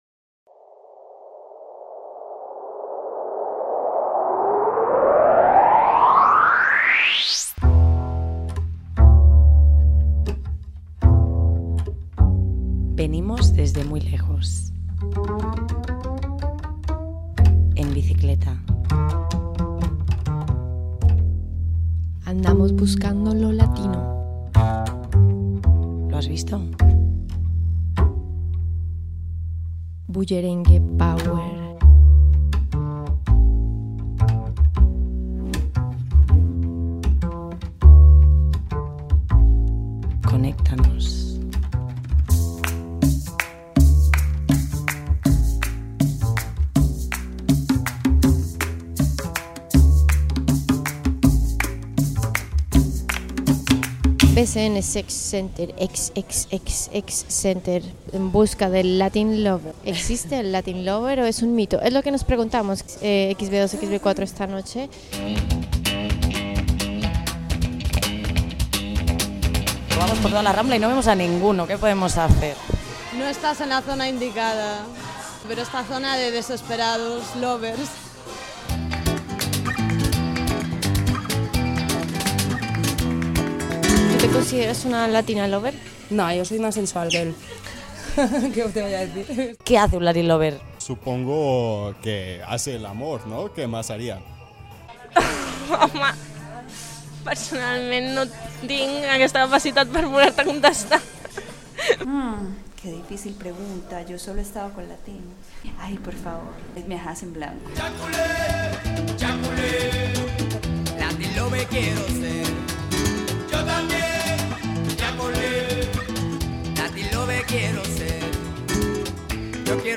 Careta del programa, espai dedicat a si existeix el "latin lover"